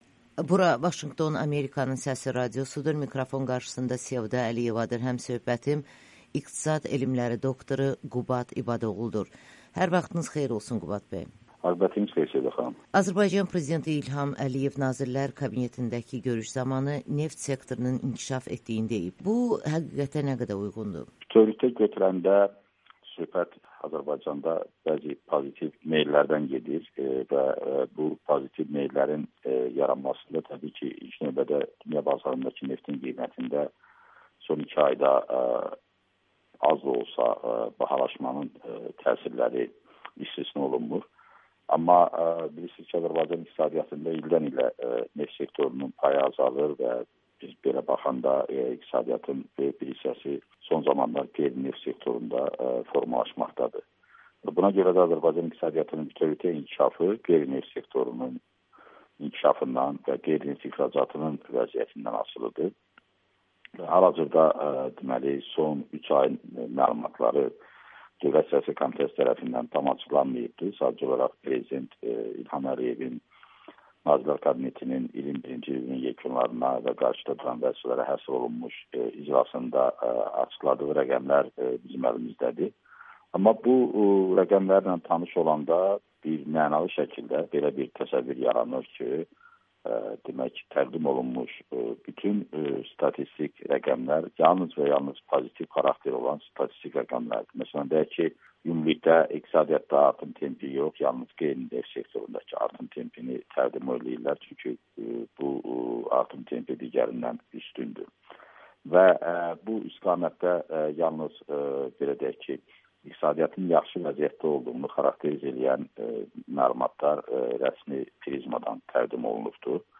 85 min iş yerinin açılmasına dair deyilənlər statistik yalandır [Audio-Müsahibə]